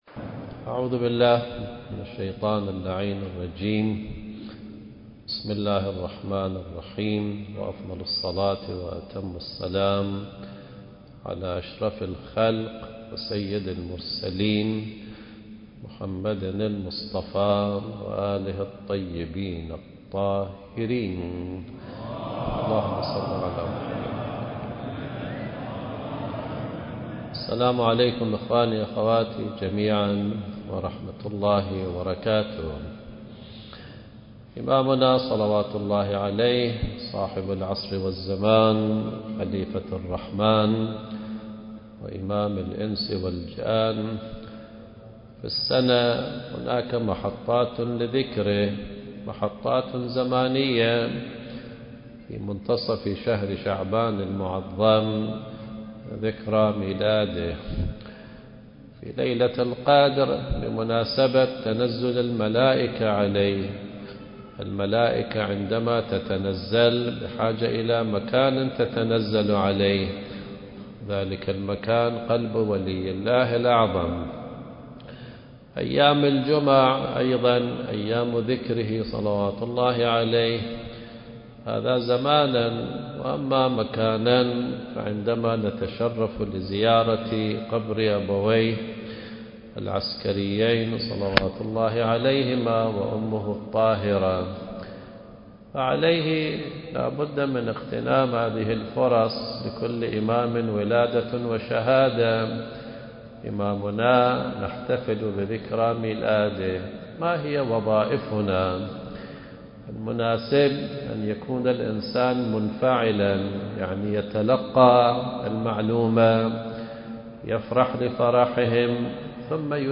المكان: مسجد الصديقة فاطمة الزهراء (عليها السلام)/ الكويت التاريخ: 2024